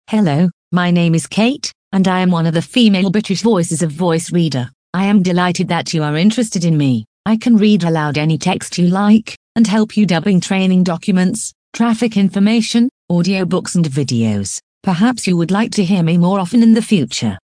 Voice Reader Home 22 English (British) - Female voice [Kate]
Voice Reader Home 22 ist die Sprachausgabe, mit verbesserten, verblüffend natürlich klingenden Stimmen für private Anwender.